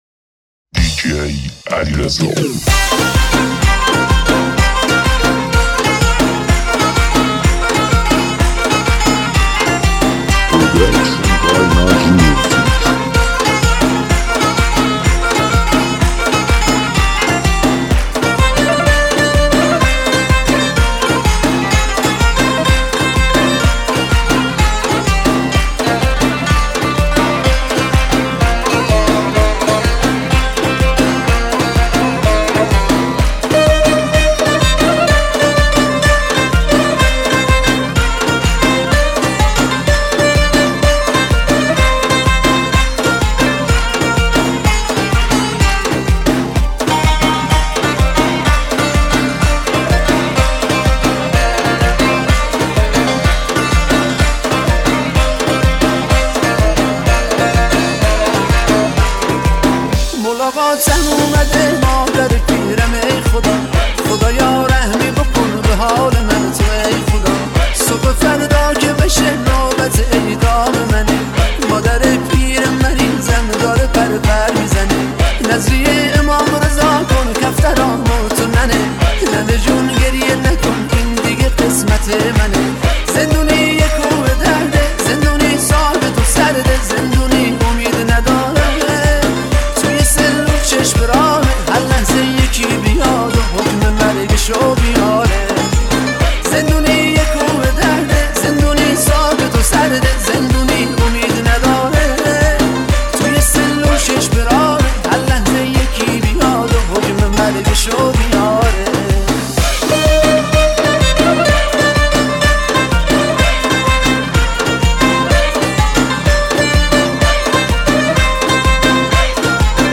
ریمیکس
ریمیکس شاد